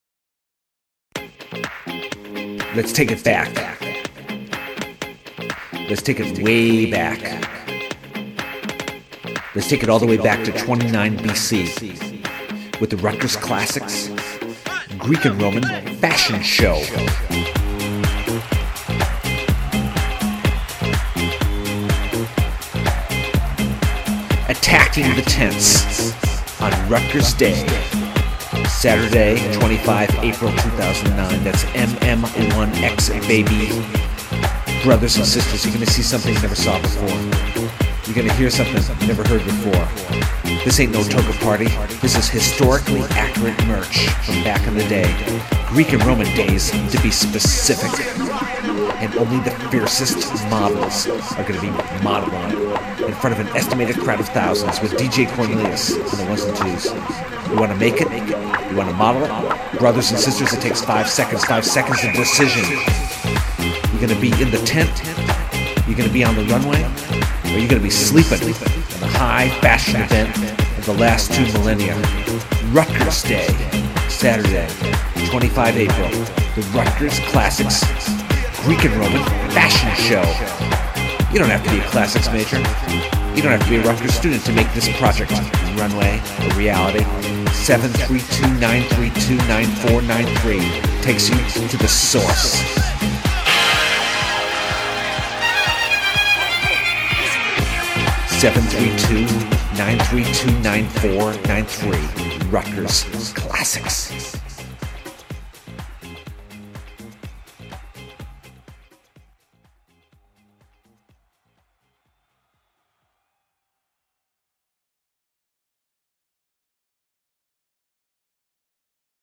rutgersdayclassicspromo.mp3